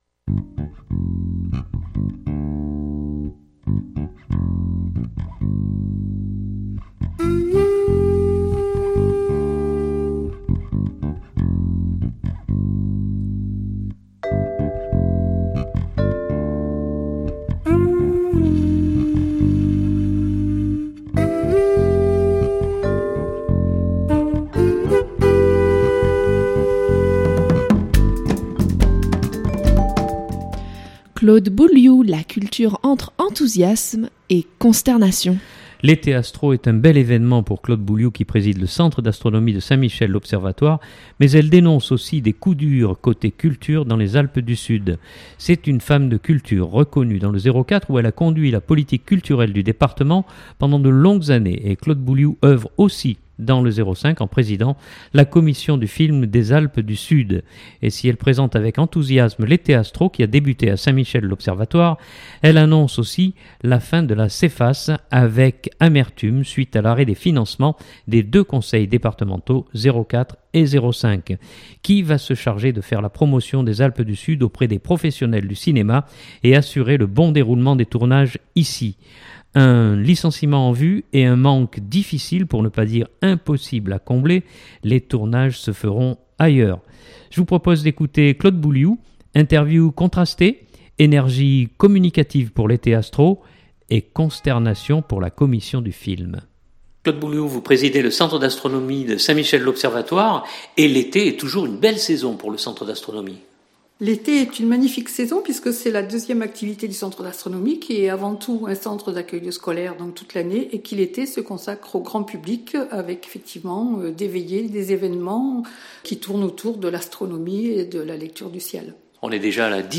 Interview contrastée : énergie communicative pour l’Eté Astro et consternation pour la Commission du Film. http